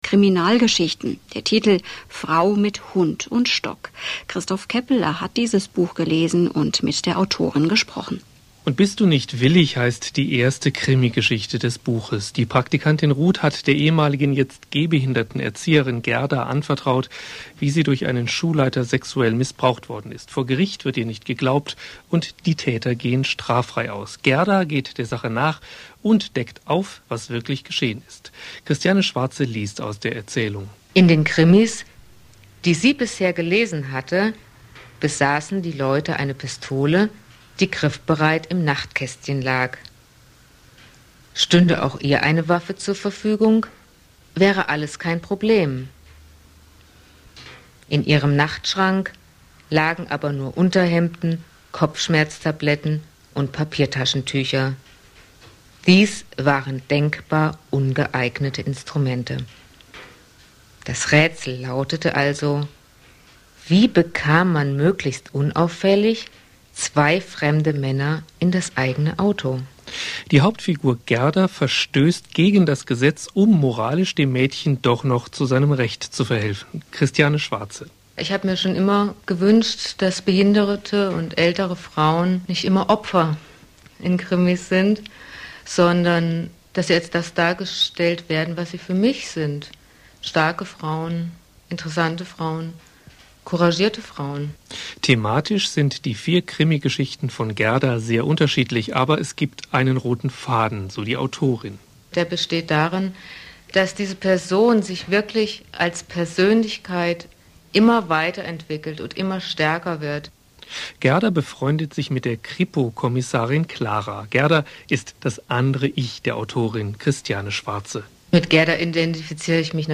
Auszug Interview Hessischer Rundfunk